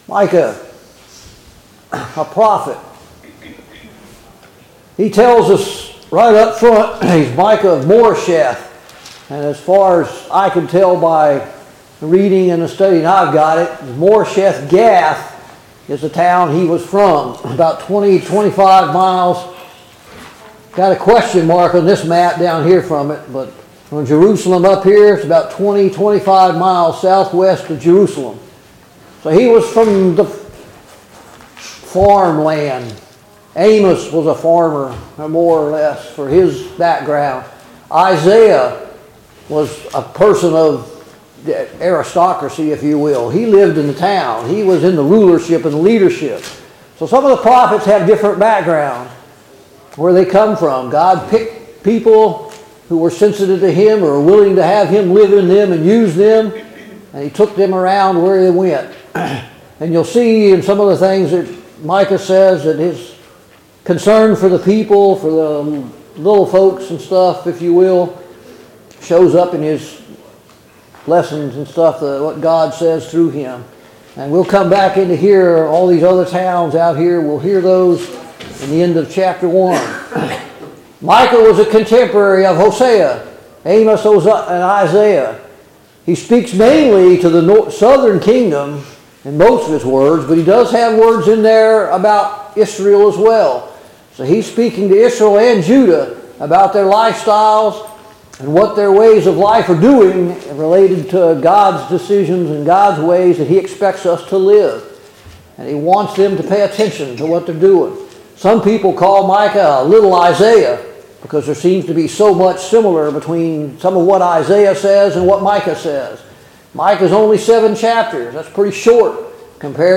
Micah Service Type: Sunday Morning Bible Class « 10.